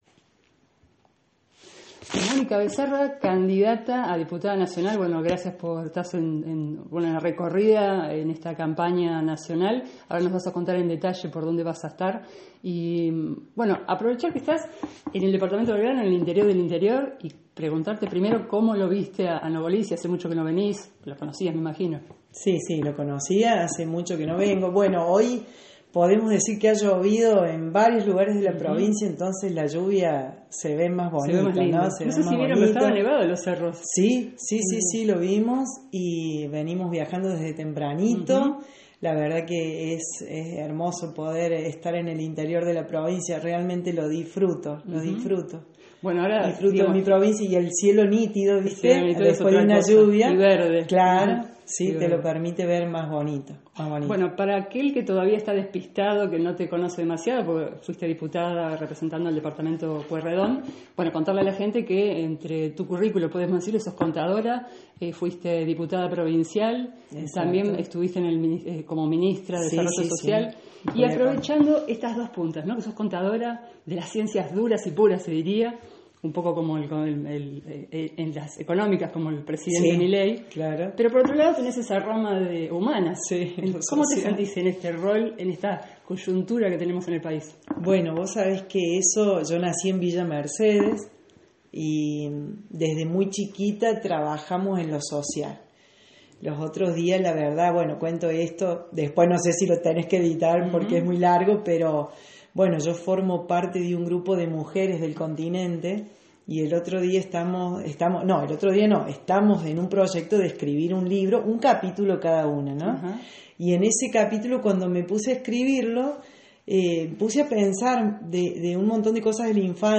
En su recorrida de campaña por el Departamento Belgrano, la candidata en primer término a diputada nacional por San Luis de La Libertad Avanza, Mónica Becerra, visitó la localidad de Nogolí y dialogó con La Posta de San Luis.
En la entrevista distendida, Becerra repasó su trayectoria y compartió sus propuestas de cara a las elecciones legislativas del 26 de octubre.